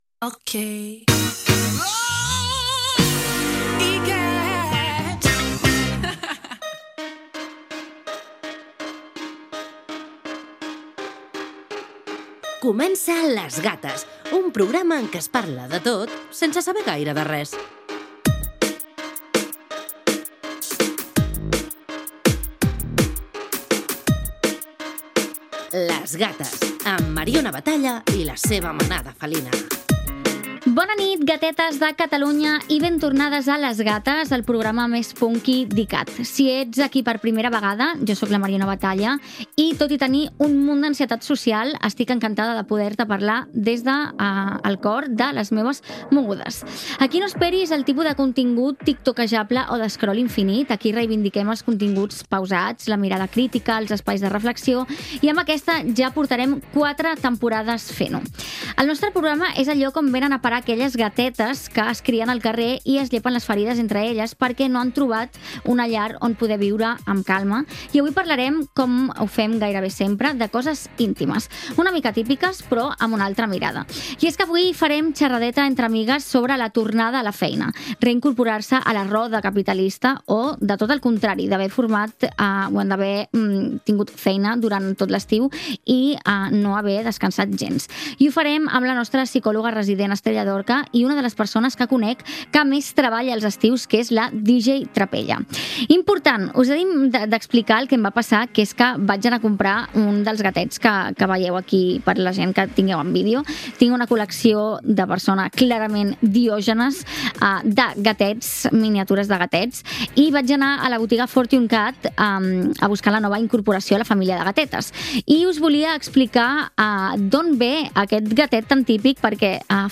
Careta del programa, inici de la quarta temporada d'emissió explicant el que s'hi fa.
Entreteniment